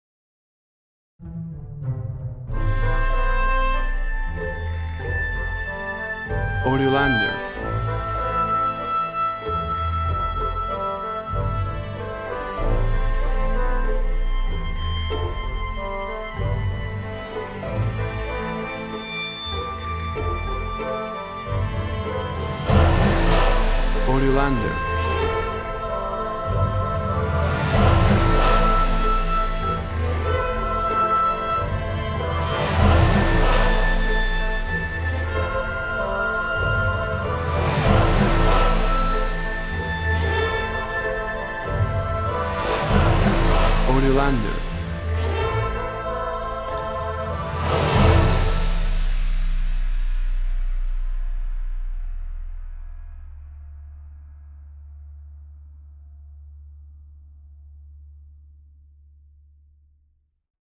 An orchestral track, Epic fantasy atmosphere.
Tempo (BPM) 100